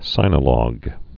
(sīnə-lôg, -lŏg, sĭnə-)